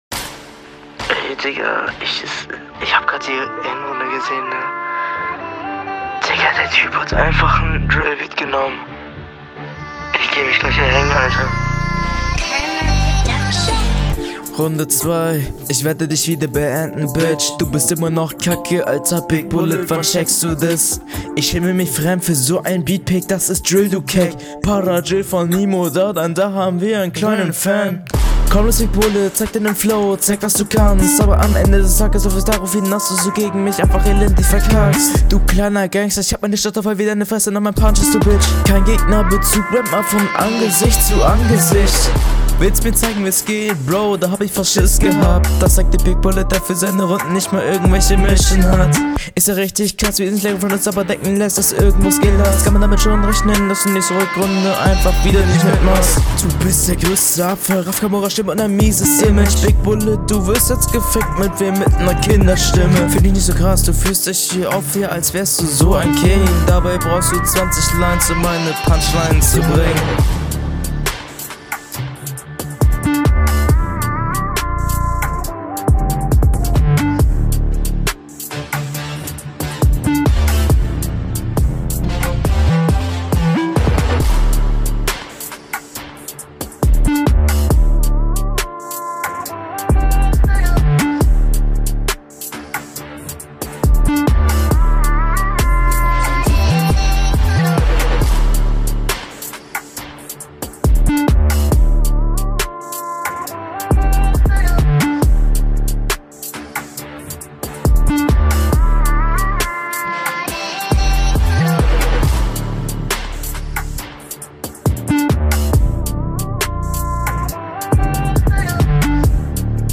Ahhh cutte doch den Beat.